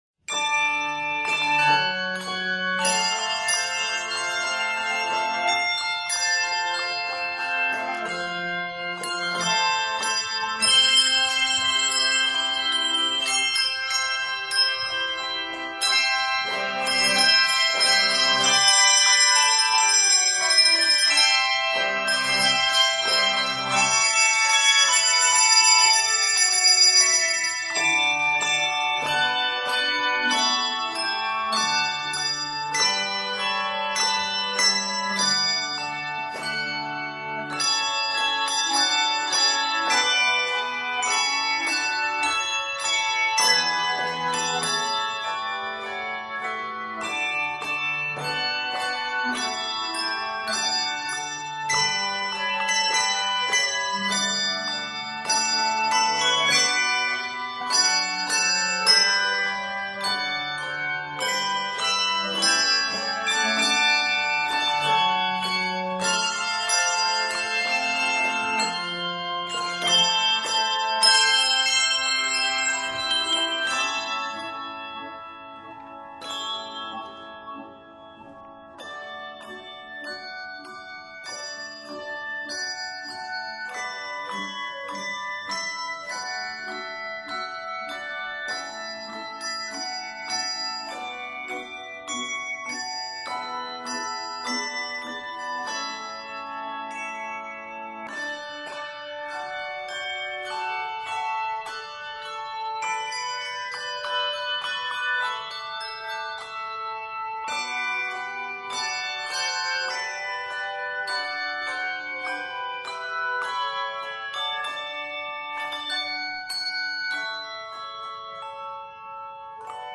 A grand fanfare opens this arrangement of the hymn tune